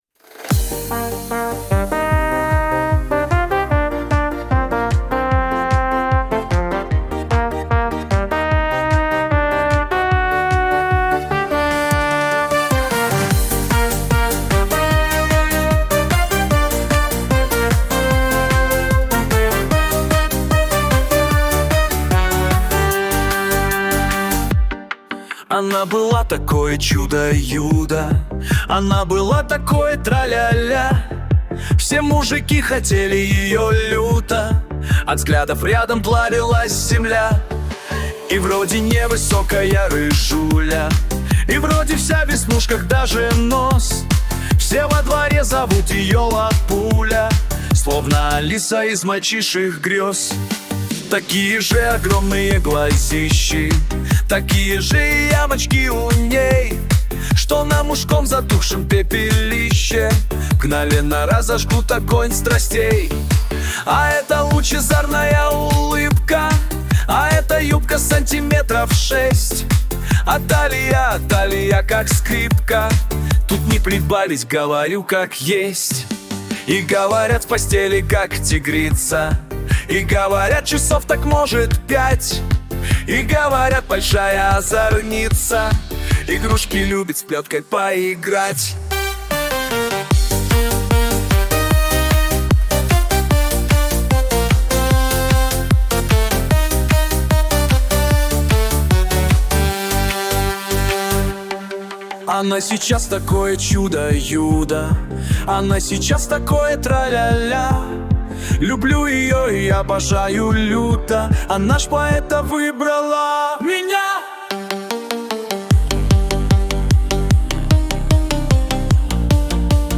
Заводная песенка о рыжей девченке